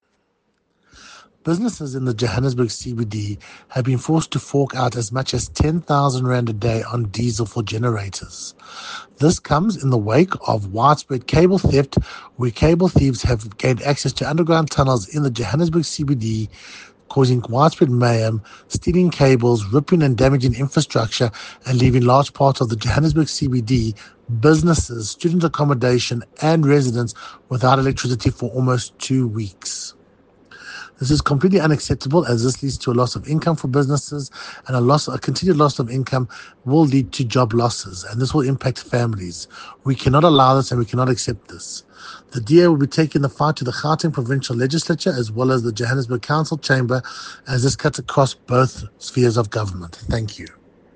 Note to Editors: Please find an English soundbite by Cllr Tyrell Meyers